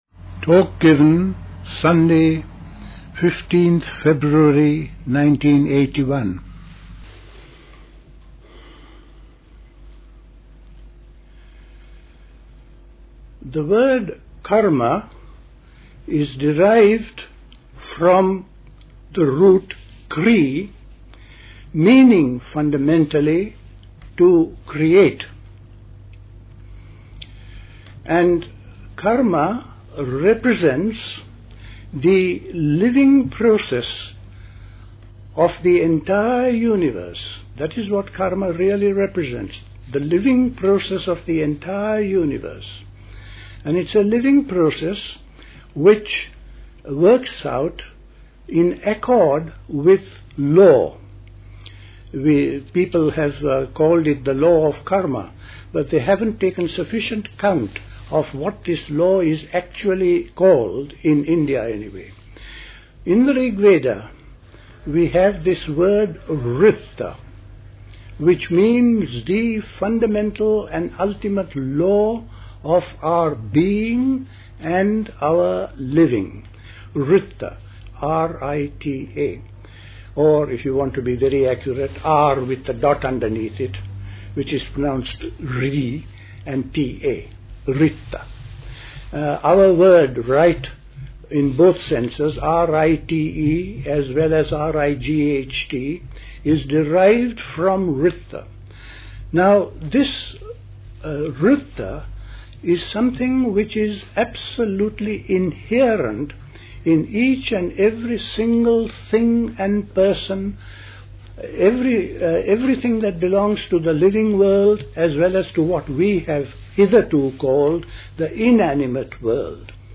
A talk
at Dilkusha, Forest Hill, London on 15th February 1981